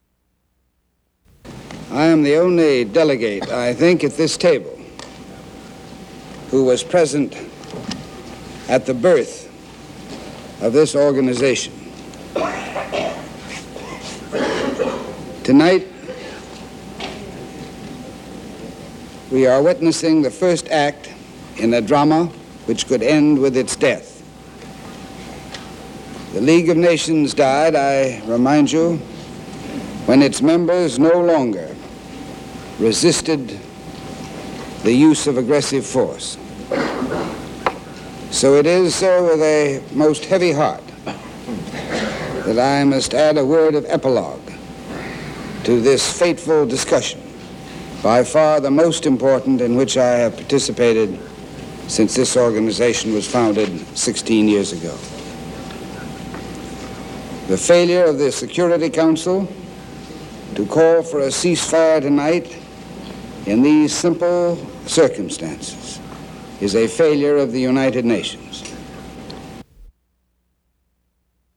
U.S. Ambassador to the United Nations Adlai Stevenson speaks at the U.N. following the vote on Goa